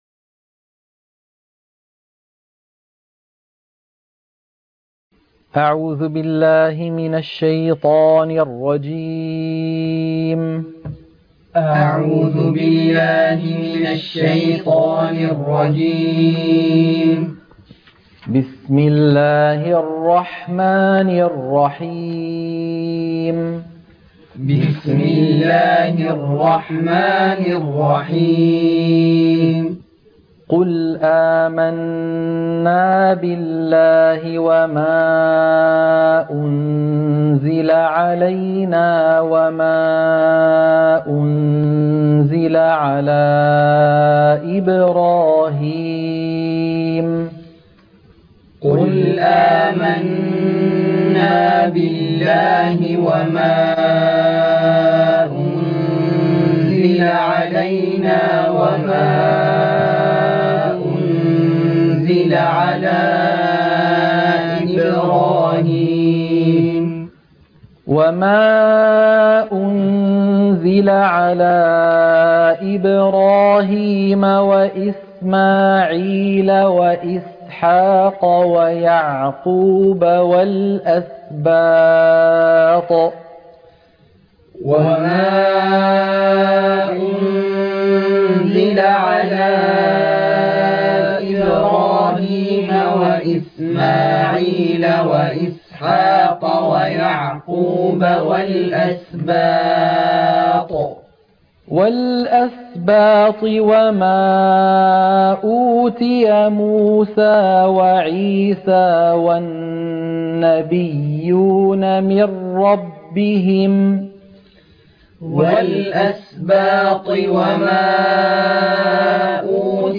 تلقين سورة آل عمران - الصفحة 61 التلاوة المنهجية - الشيخ أيمن سويد